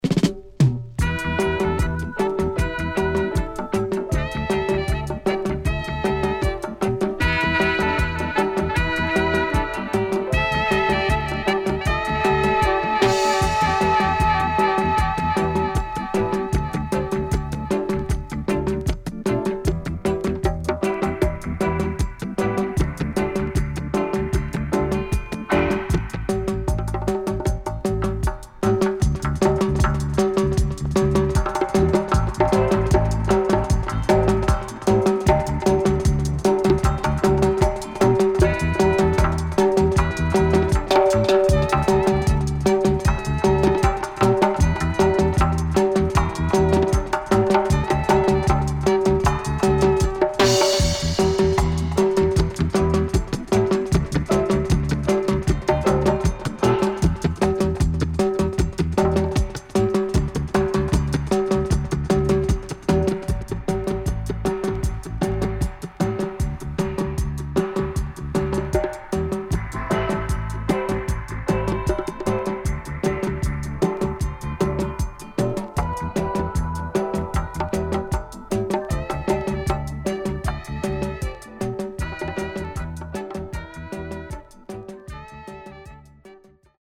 HOME > DUB